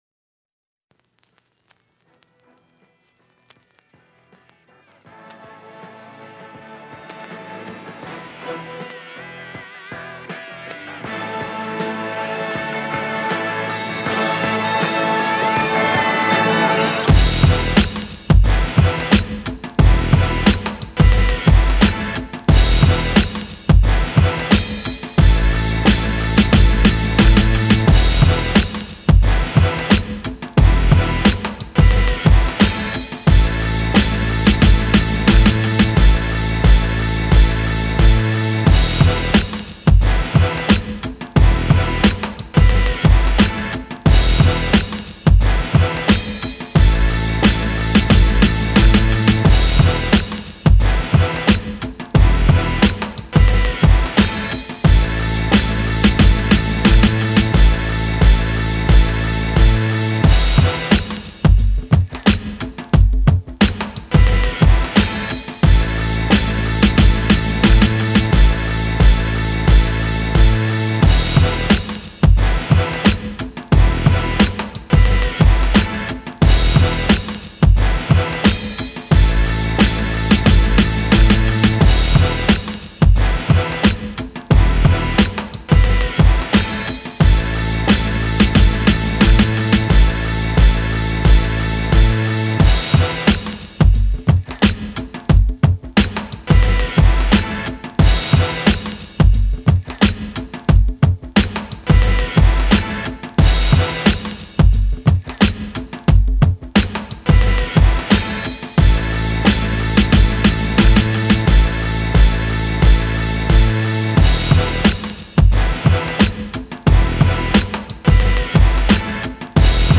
6 Styl: Hip-Hop Rok